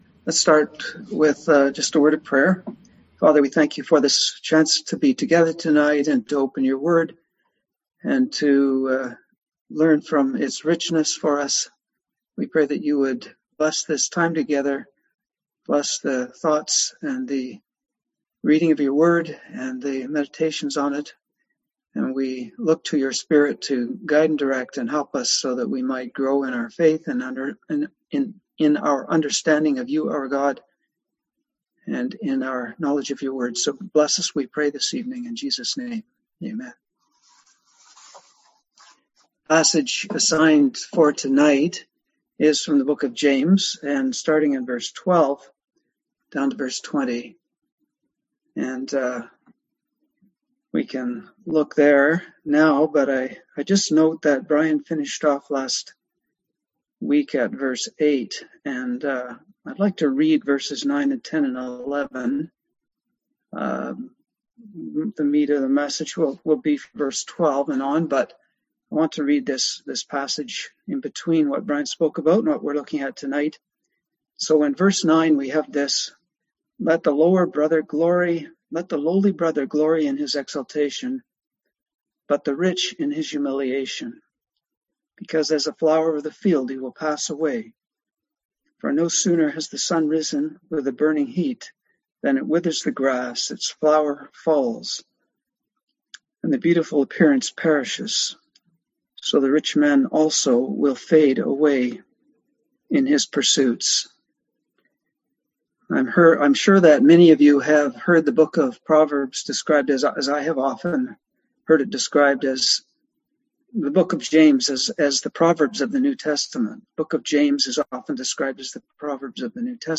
Service Type: Seminar Topics: Humility